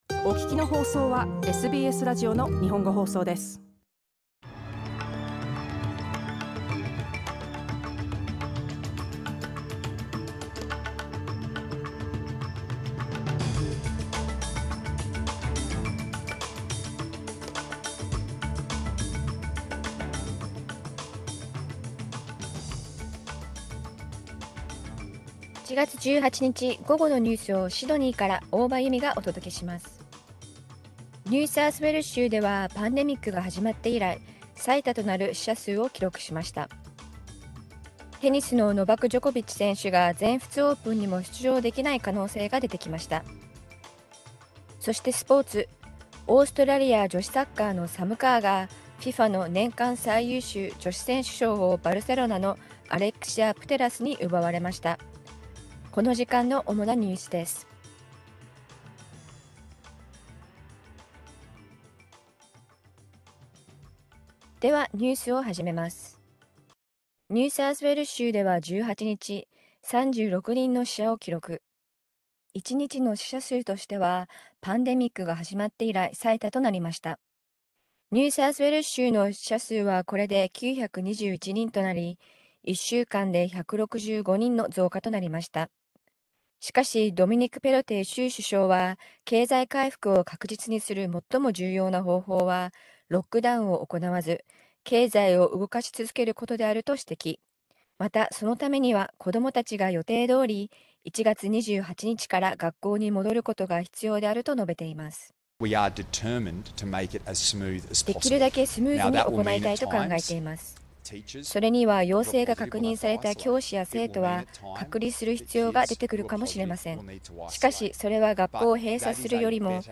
1月18日午後のニュース
１月18日火曜日午後のニュースです。